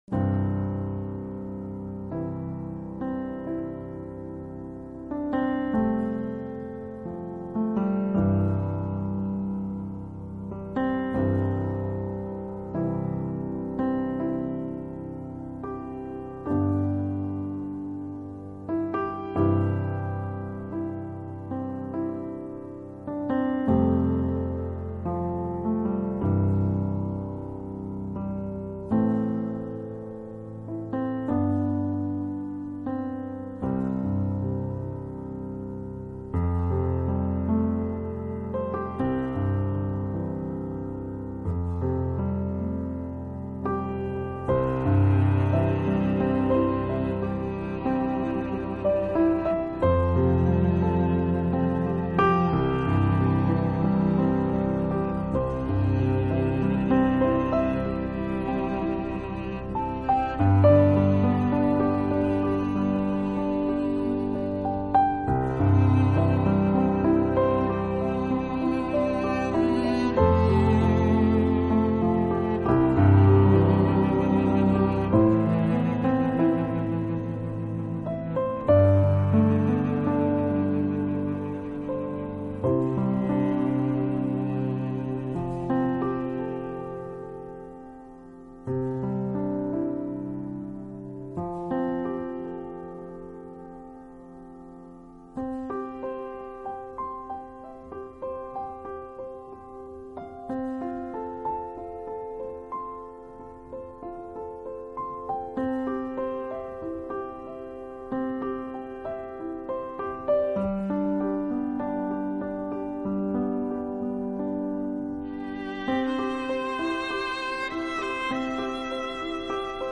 音乐流派：New Age, Piano